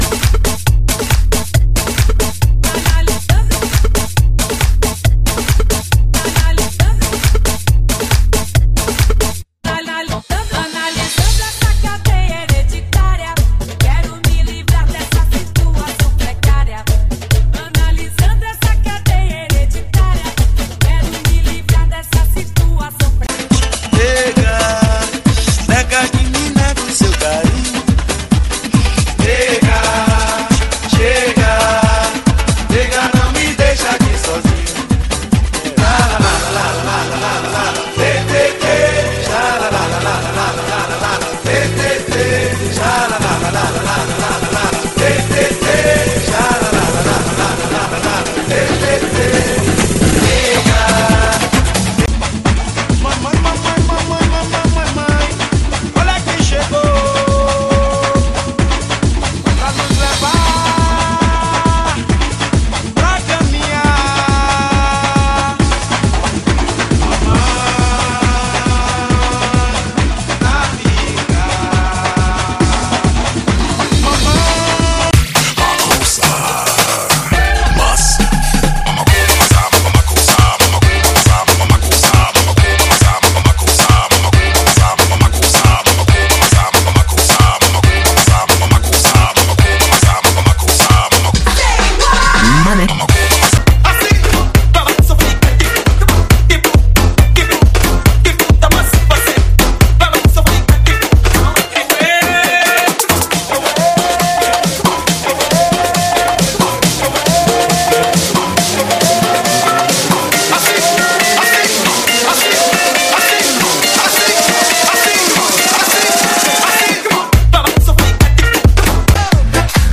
GENERO: ELECTRO, BATUCADA, REMIX, SAMBA, BRASILEÑO